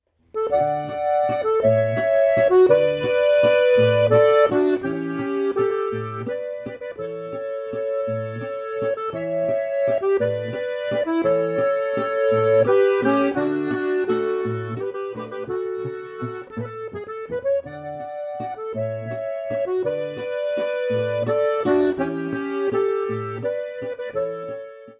4-reihige steirische Harmonika
Volksweisen